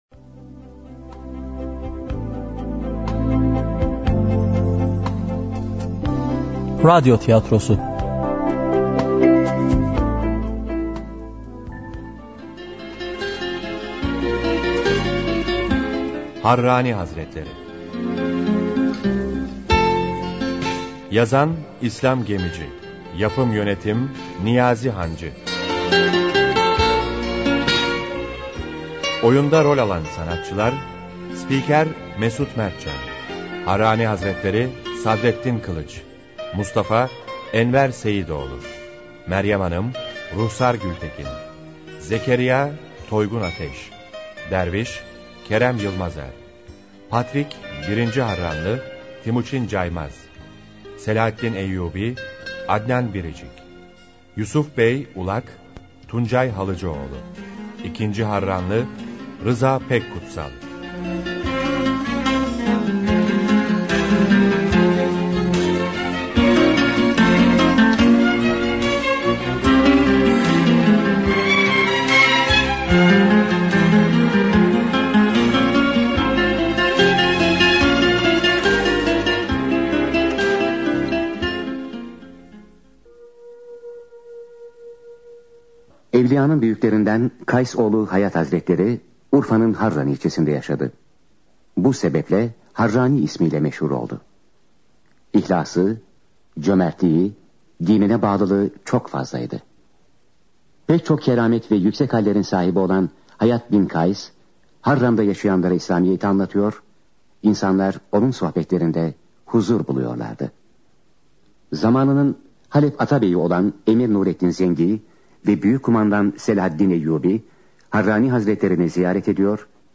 Harrani-Hazretleri-radyo-tiyatrosu-.mp3